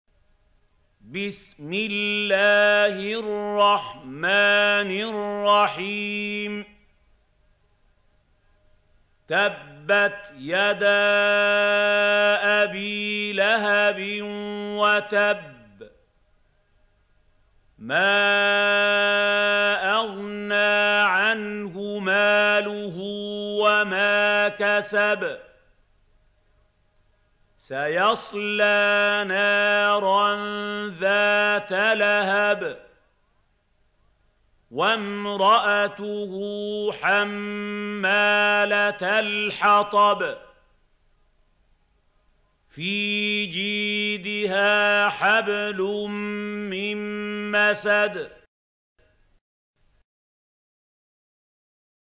سورة المسد | القارئ محمود خليل الحصري - المصحف المعلم